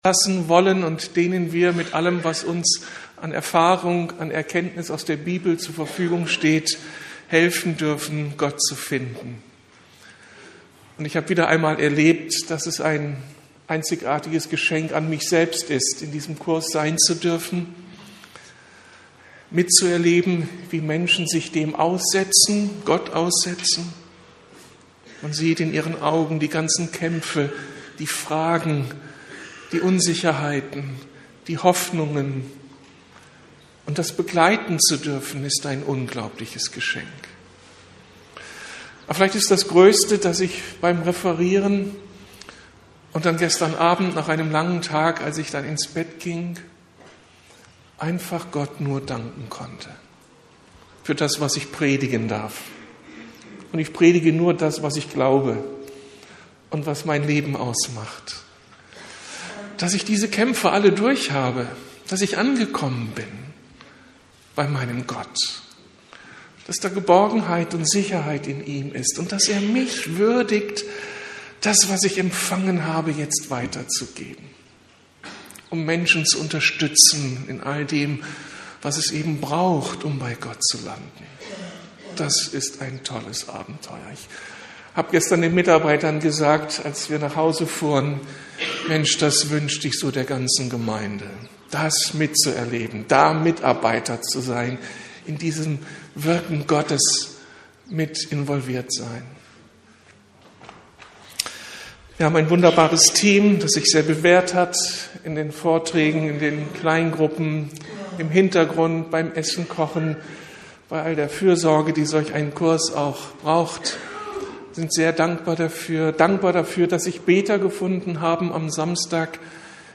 Gott als Vater entdecken ~ Predigten der LUKAS GEMEINDE Podcast